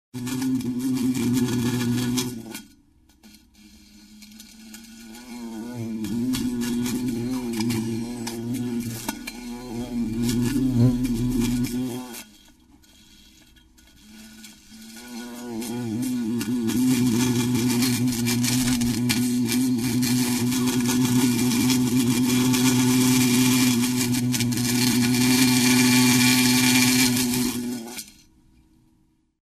Звуки насекомых
Жужжание пчелы в стеклянной банке